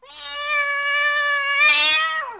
جلوه های صوتی
دانلود صدای حیوانات جنگلی 23 از ساعد نیوز با لینک مستقیم و کیفیت بالا